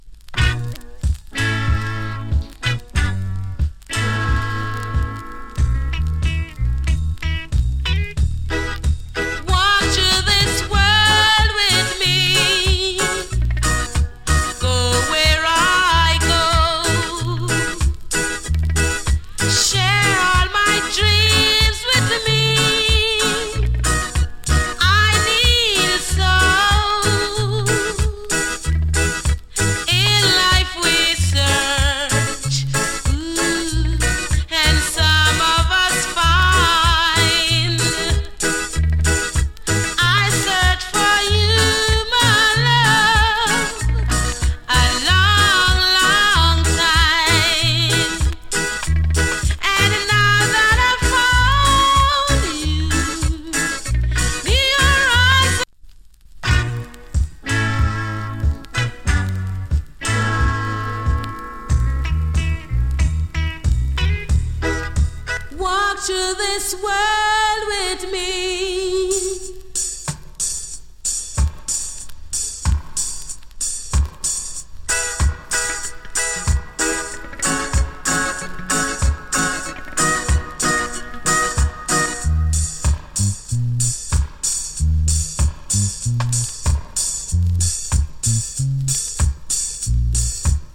チリ、ジリノイズ少し有り。
NICE FEMALE VOCAL REGGAE !